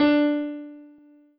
piano-ff-42.wav